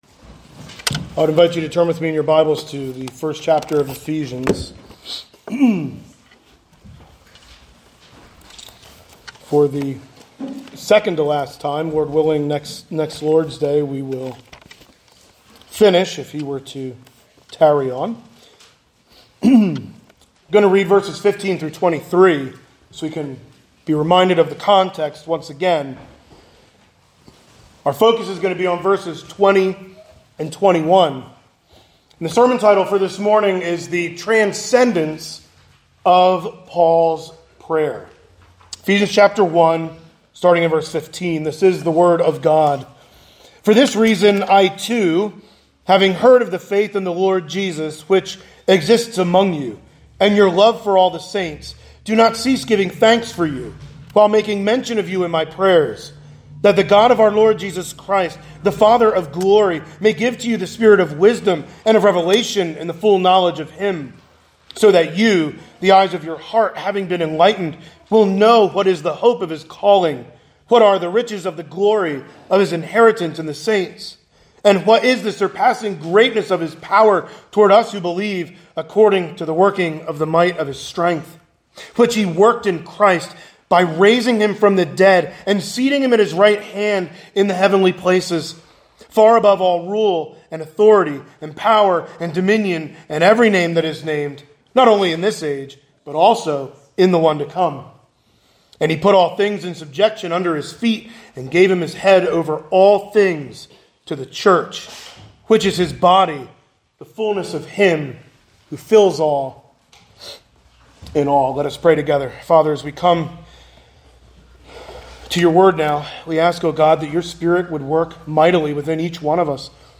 Sunday Morning Sermons | Zionsville Bible Fellowship Church